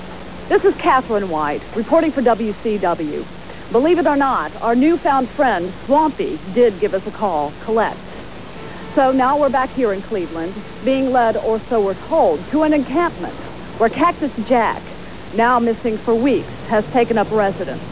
Some time after Jack was powerbombed, WCW began showing vignettes of a bogus news reporter named Catherine White who was searching of Cactus, who no one had seen since the match with Vader.